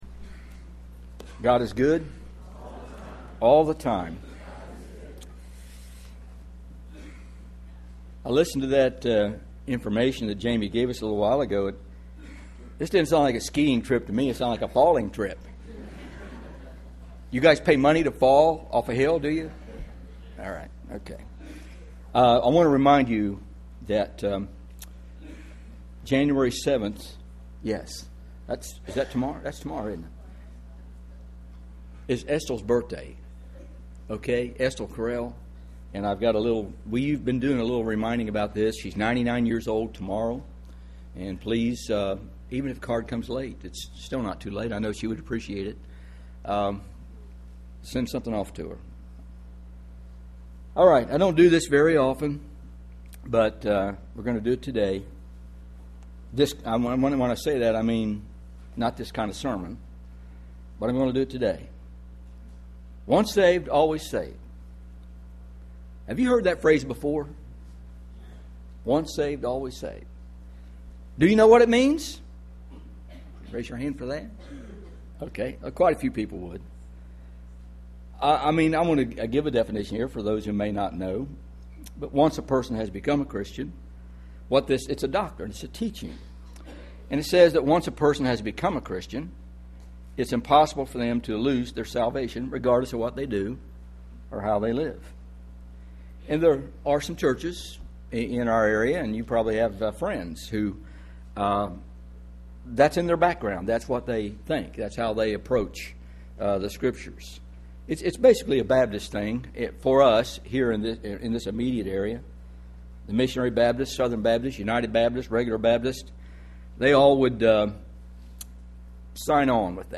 Teaching: Once Fallen/Always Fallen Heb 6:1-6